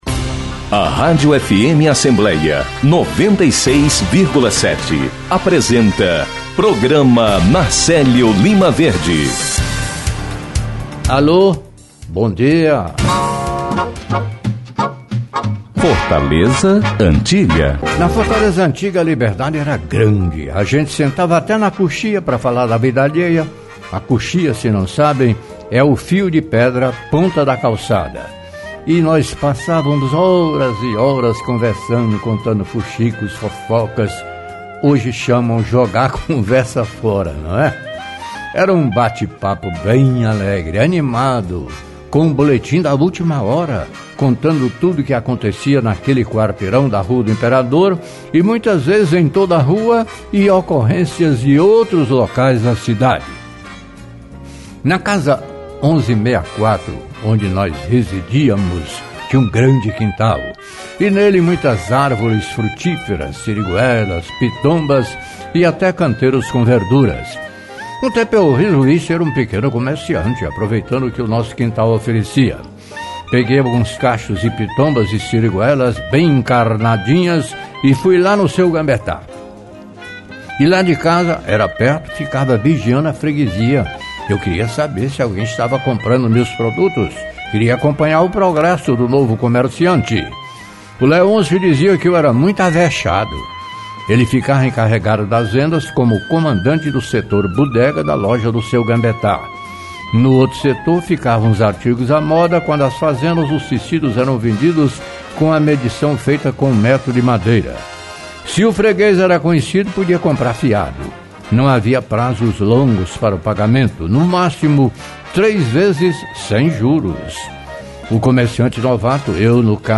entrevista o primeiro secretário da Assembleia Legislativa, deputado Evandro Leitão (PDT).